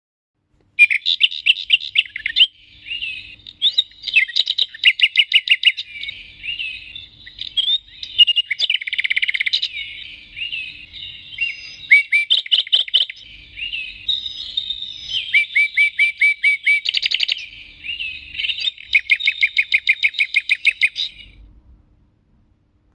From barking dogs to meowing cats and chirping birds, each clock has its own unique voice.